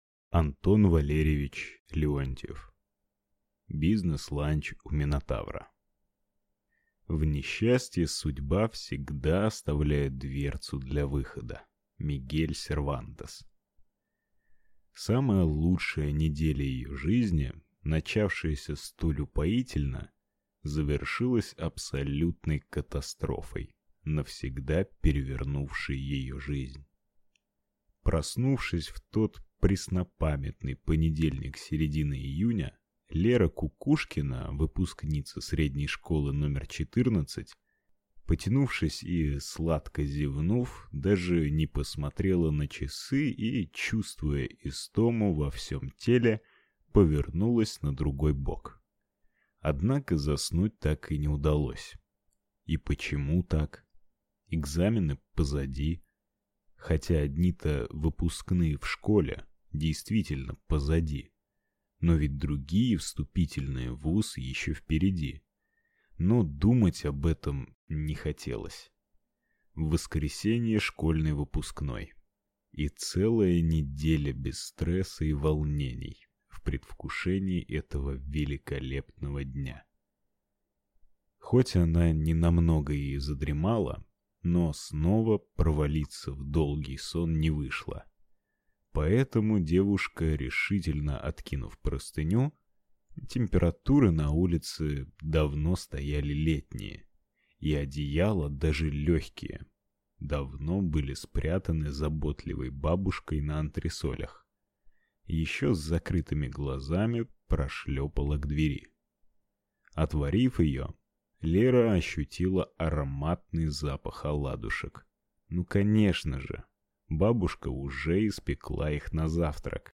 Аудиокнига Бизнес-ланч у Минотавра | Библиотека аудиокниг